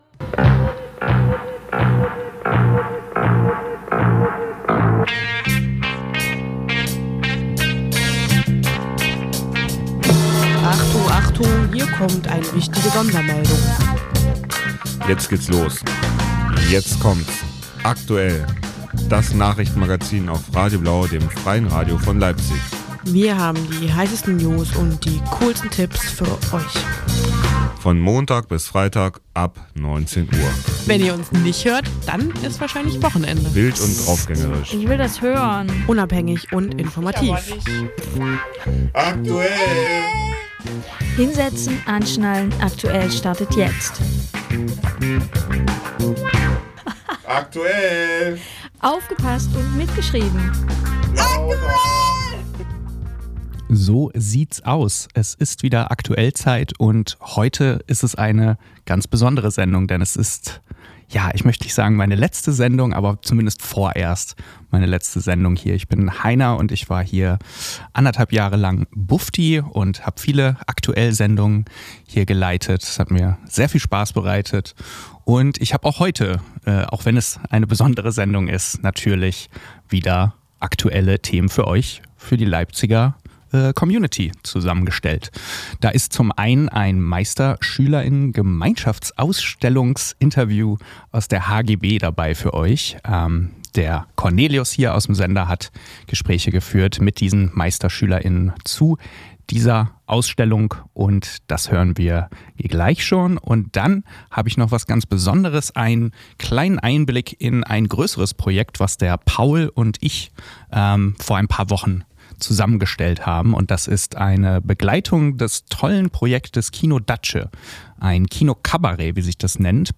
September 2025 Das wochentägliche Magazin am Abend, mit Interviews und Beiträgen aus Politik und Kultur sowie Veranstaltungstipps. Heute u.a. mit einem Beitrag zum M25 – Festival der Meisterschüler*innen der HGB , welches ab morgigen Freitag eine dezentrale Ausstellung der Absolvent\*innen zeigt.